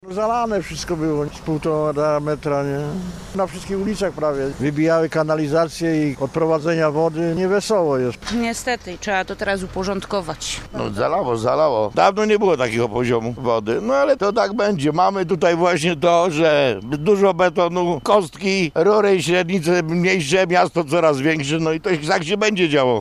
Tak ulewnego deszczu w Ostrowie dawno nie było - mówią mieszkańcy: